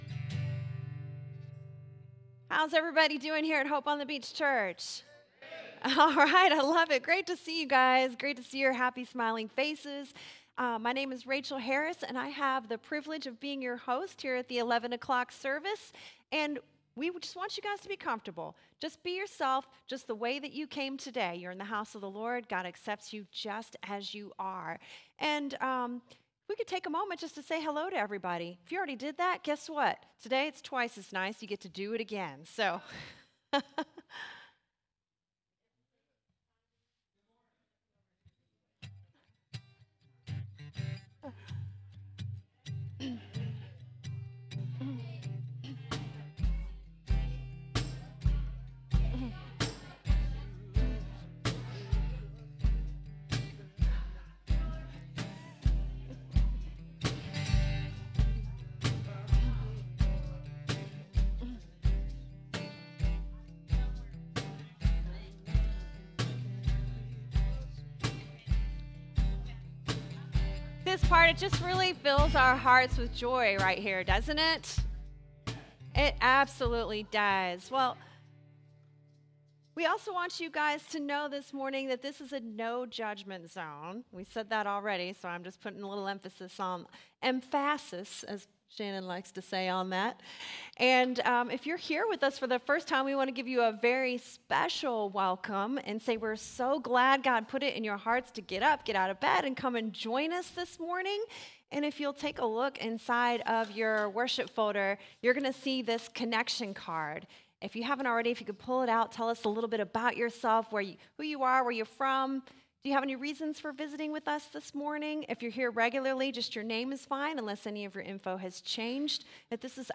Download Download Reference Galatians 3:1-9, Ephesians 2:8-9 Sermon Notes CLICK HERE FOR NOTES.pdf SERMON DESCRIPTION There are an estimated 4,200 different religions in the world; Christianity being one of them.